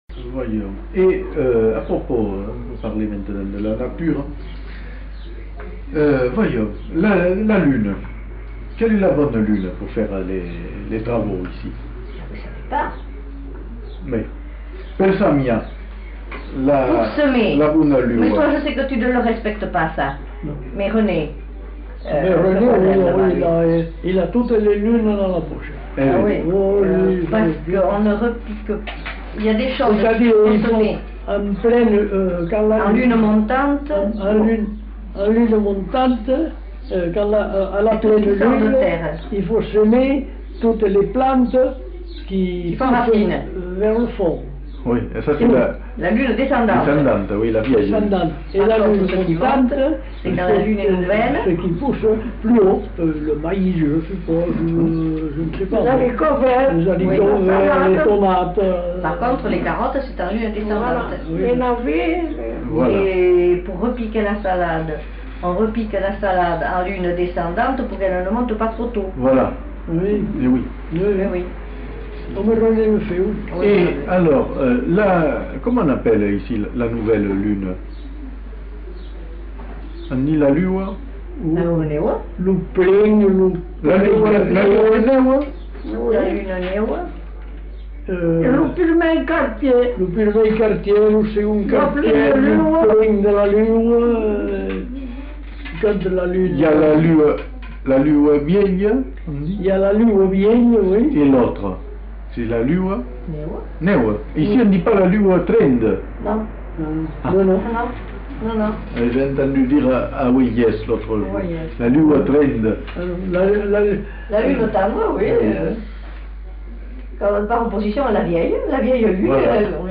Lieu : Captieux
Genre : témoignage thématique